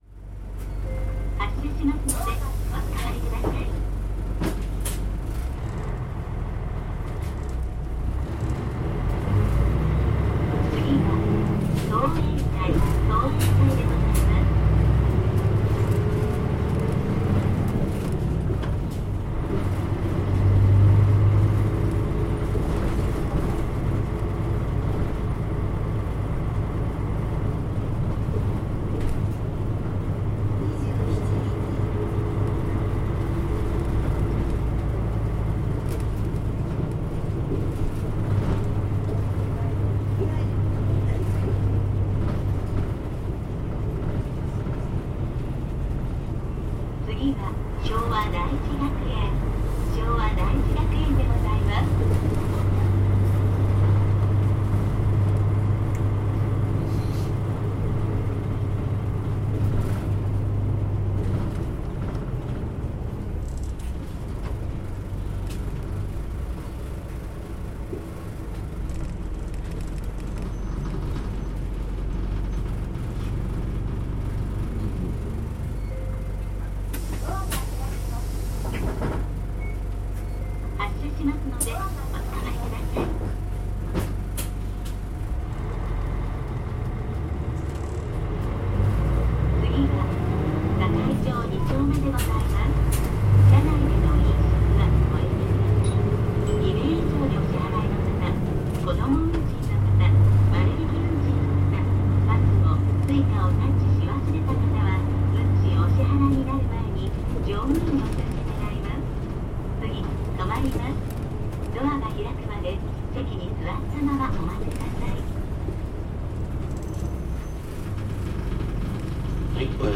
全国路線バス走行音立川バス
KL代後期，ターボ付きのMP37です．発進時のガラガラ音が強く，OD付5速で関東地方では標準的な走行音になります．